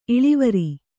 ♪ iḷivari